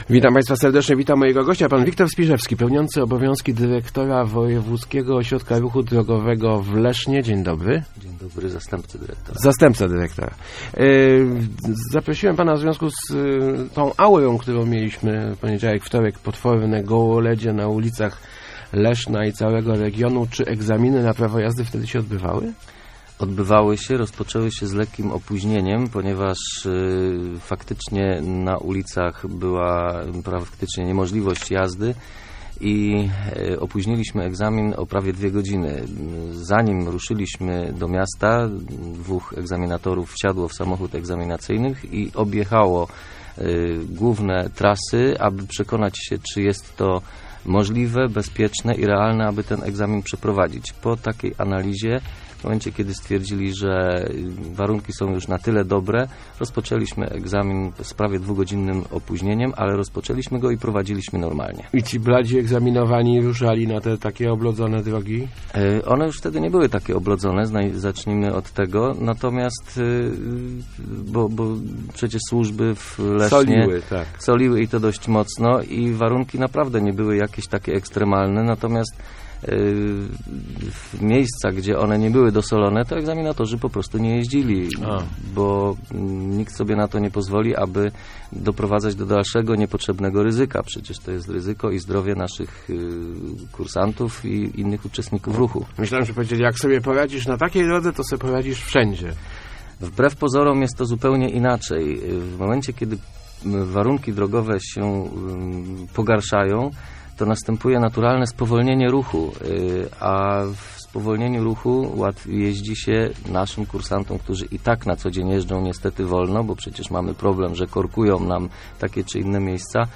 W rozmowie dowiedzieli�my si� te� o nowo�ci w leszczy�skim WORD-zie - mo�liwo�ci ponownego zdawania egzaminu teoretycznego w tym samym dniu.